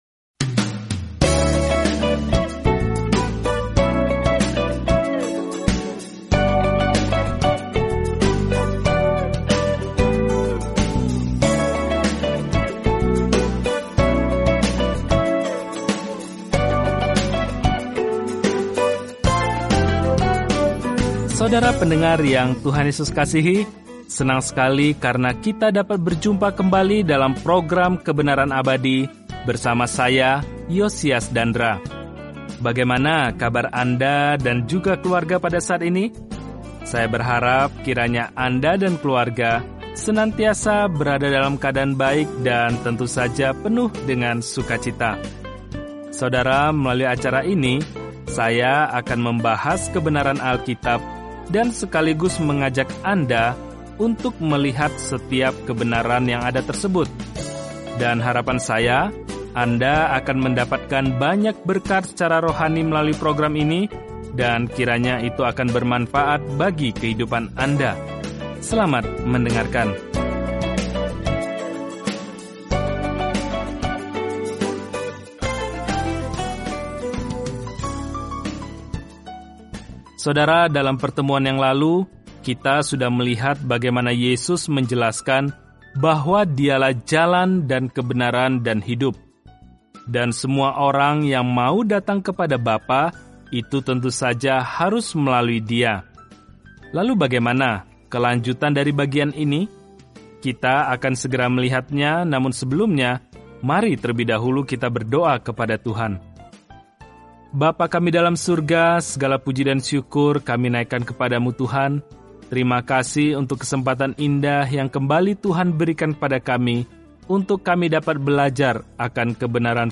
Firman Tuhan, Alkitab Yohanes 14:7-31 Hari 27 Mulai Rencana ini Hari 29 Tentang Rencana ini Kabar baik yang dijelaskan Yohanes unik dibandingkan Injil lainnya dan berfokus pada mengapa kita hendaknya percaya kepada Yesus Kristus dan bagaimana memiliki kehidupan dalam nama ini. Telusuri Yohanes setiap hari sambil mendengarkan pelajaran audio dan membaca ayat-ayat tertentu dari firman Tuhan.